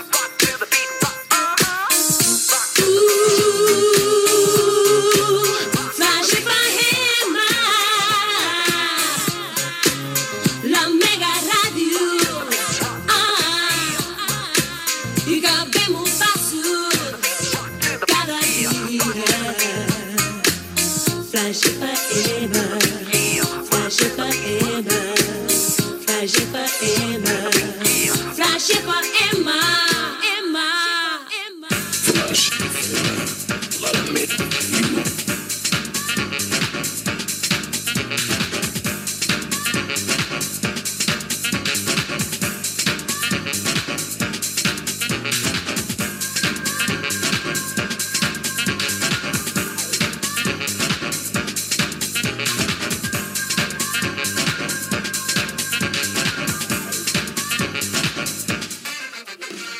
Indicatiu cantat de la ràdio